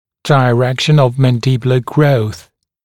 [dɪ’rekʃn əv ˌmæn’dɪbjulə grəuθ] [daɪ-] [ди’рэкшн ов ˌмэн’дибйулэ гроус] [дай-] направление роста нижней челюсти